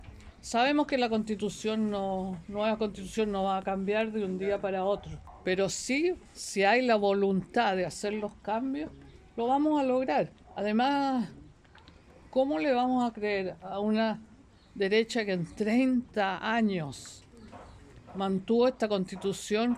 En un desayuno con vecinos y autoridades de Renca, la exsenadora y expresidenta de la Democracia Cristiana, Carmen Frei, se sumó a la campaña de despliegue nacional del Apruebo, donde se refirió a los desafíos que tienen en lo que queda de campaña.